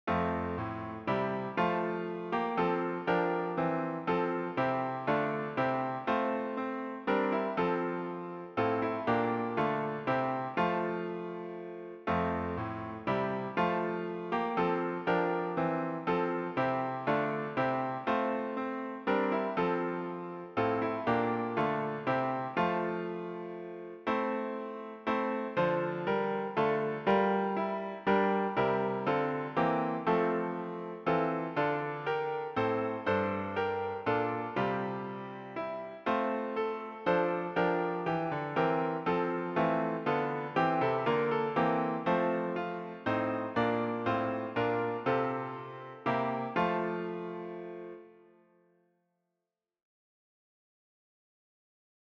Another tune that works is the lovely and well-known HYFRYDOL.